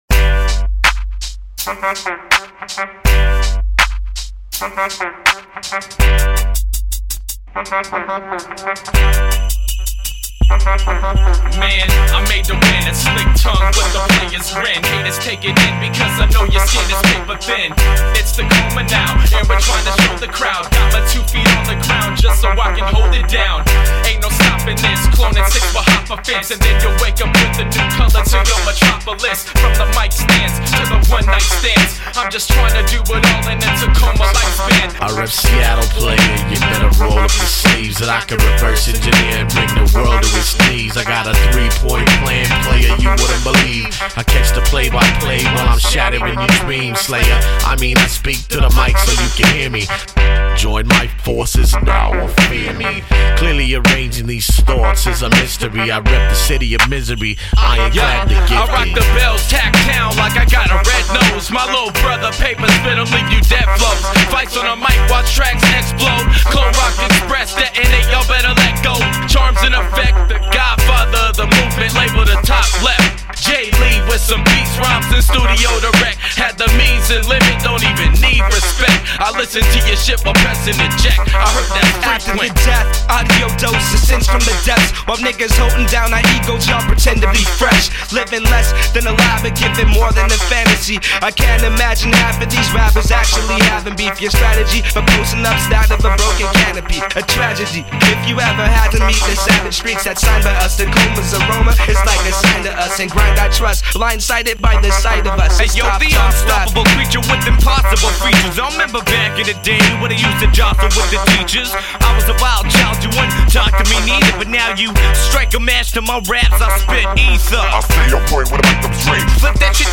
hip hop collective